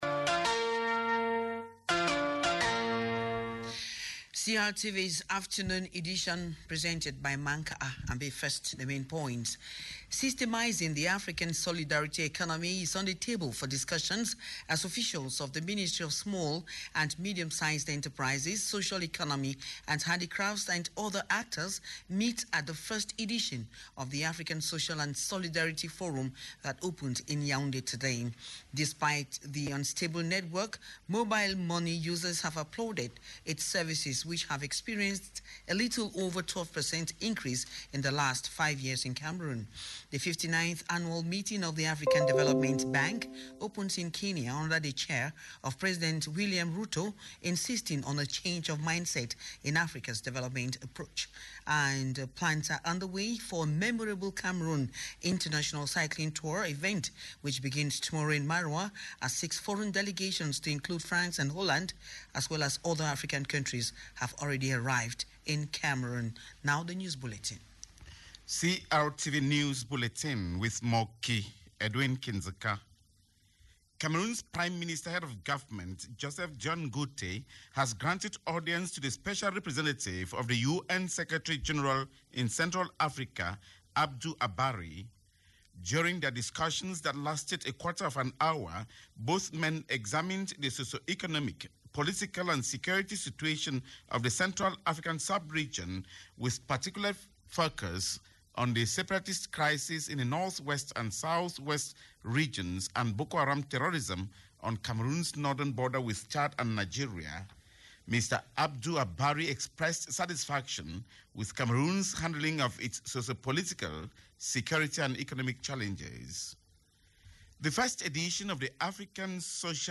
The 3pm News of May 29, 2024 on CRTV - CRTV - Votre portail sur le Cameroun